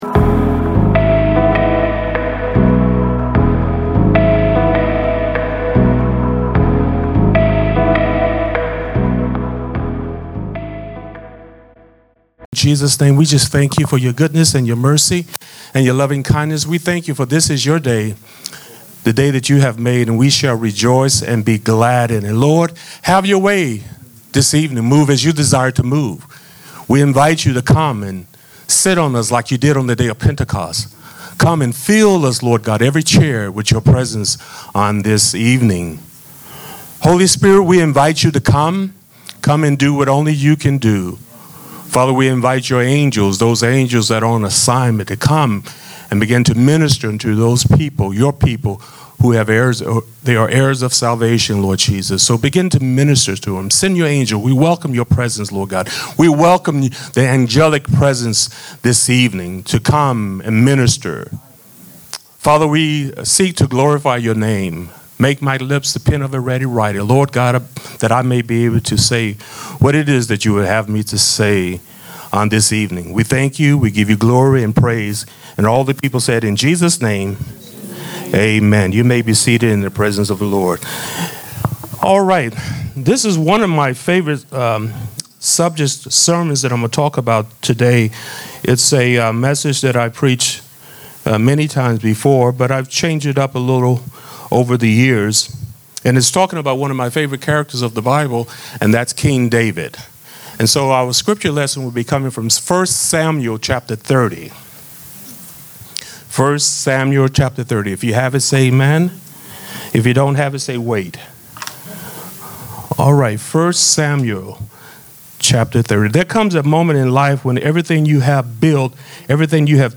Sermons | Forerunner Church